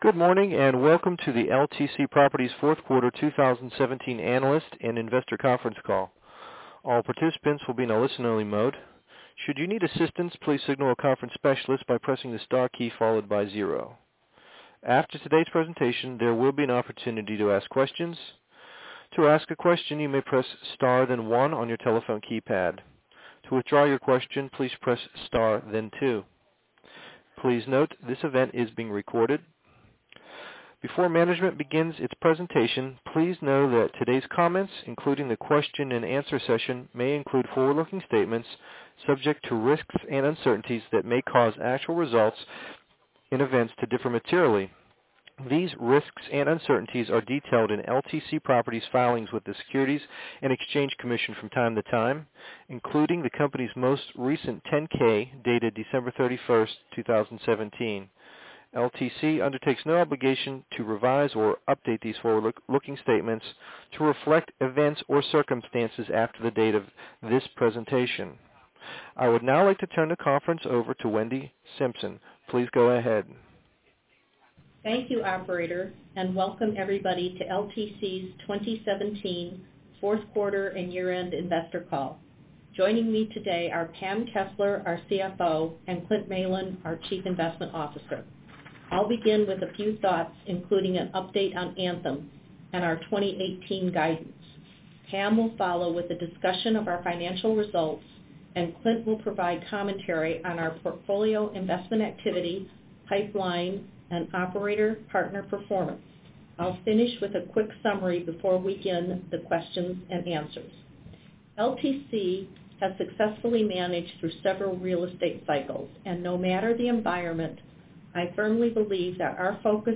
Earnings Webcast FY 2017 Audio
Audio-Replay-of-LTC-Properties-Inc-Q4-2017-Earnings-Call.mp3